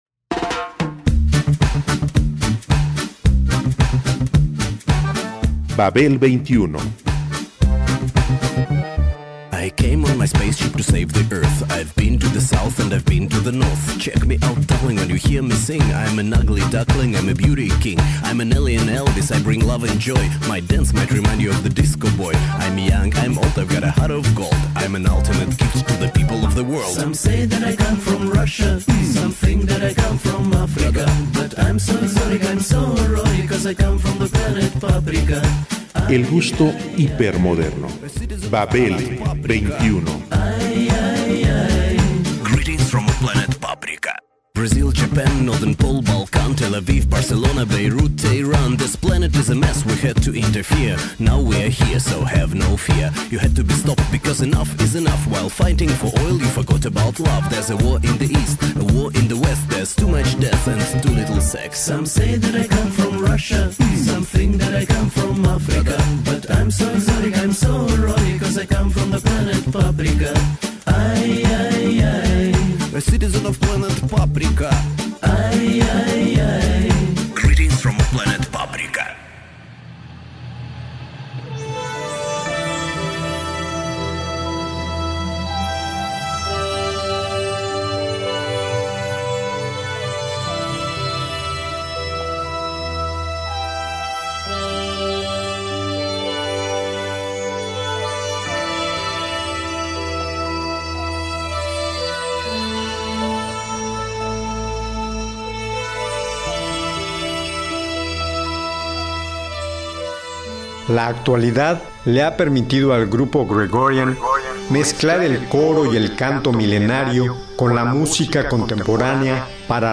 La actualidad le ha permitido al grupo Gregorian mezclar el coro y el canto milenario con la música contemporánea para resistir la realidad.